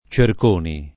cerconi